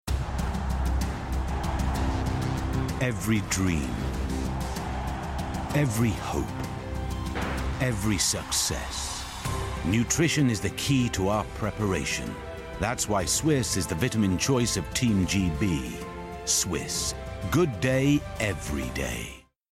Yorkshire
Male
Deep
Dry
Gravelly
SWISSE COMMERCIAL